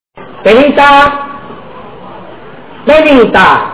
commedia brillante dialettale
Interrotta più volte dagli applausi e dalle risate, la commedia ha rappresentato un netto stacco dalla quotidianità, un tuffo nel mondo della comicità popolare immediata e diretta.